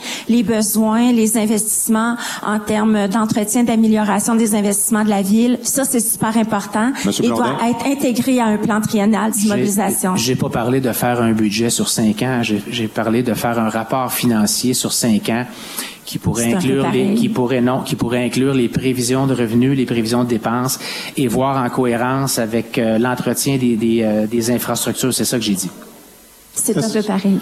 Quelques flèches ont été lancées l’un envers l’autre lors du débat entre les candidats à la mairie de Bécancour qui se tenait mercredi en soirée.